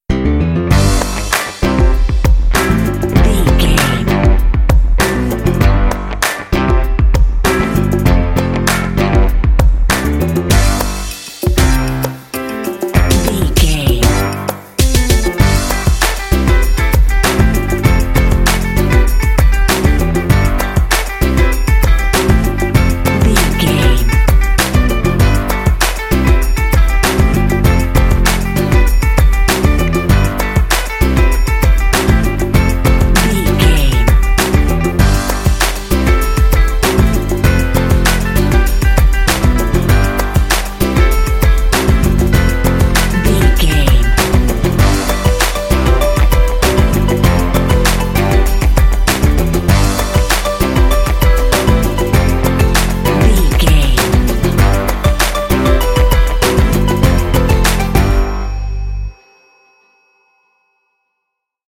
This cute hip hop track is great for kids and family games.
Uplifting
Ionian/Major
bright
happy
bouncy
urban
piano
bass guitar
electric guitar
drums
electric organ
synthesiser
strings
percussion
Funk
underscore